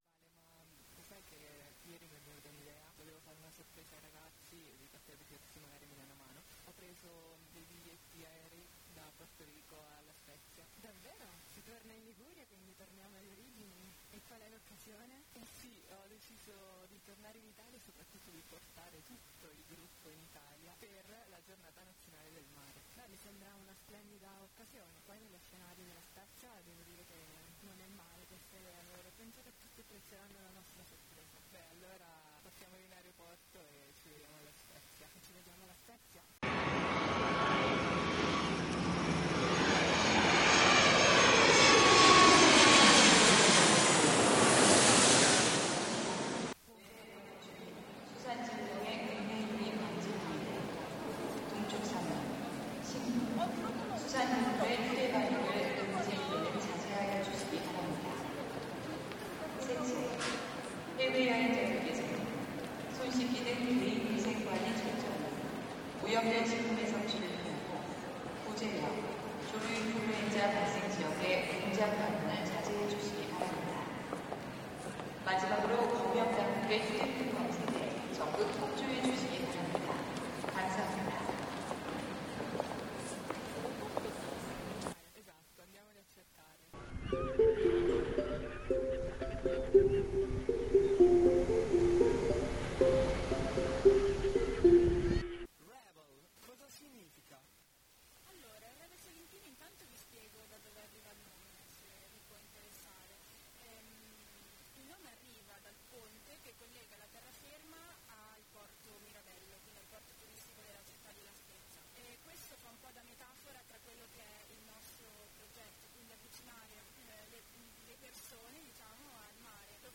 In questa puntata speciale ci spostiamo a La Spezia, dove lo scorso 11 Aprile abbiamo partecipato alla Giornata Nazionale del Mare. A fare da cornice all'episodio, le storie del Revel Sailing Team, che ci ha ospitato e raccontato cosa significa vivere il mare ogni giorno tra vela, formazione e passione.